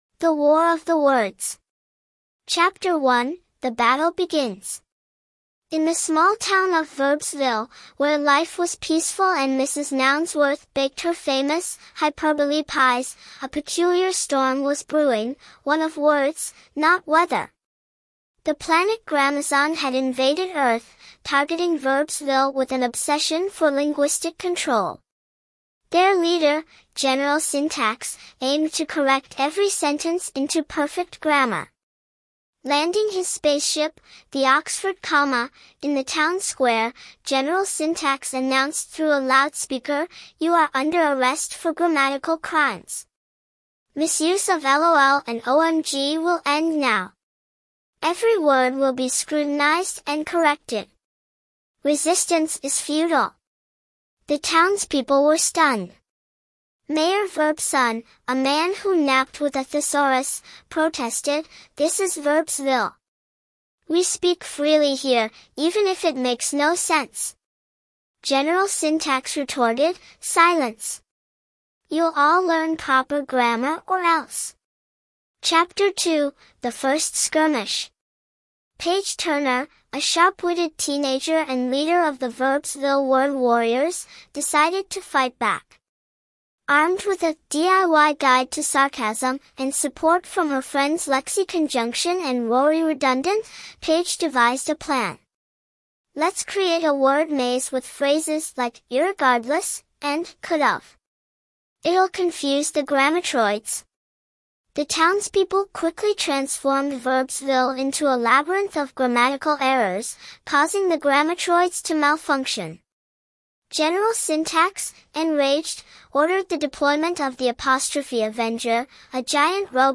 More Audio Books